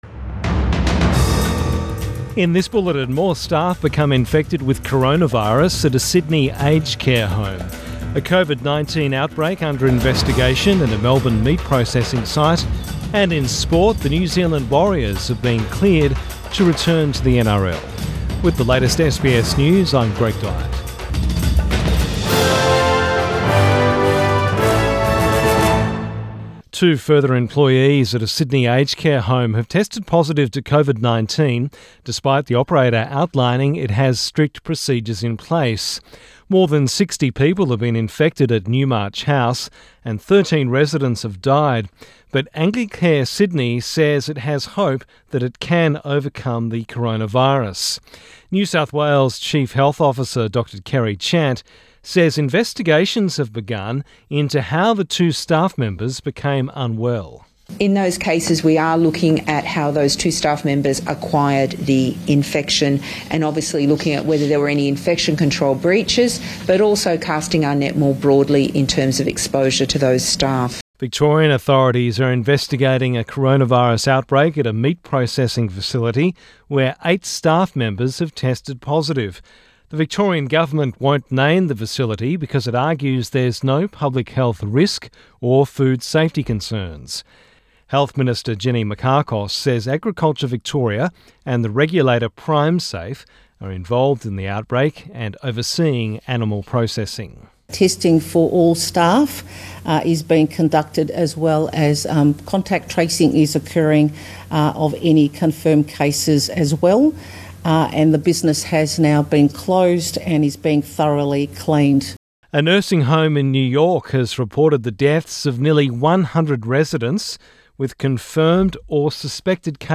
PM bulletin 2 May 2020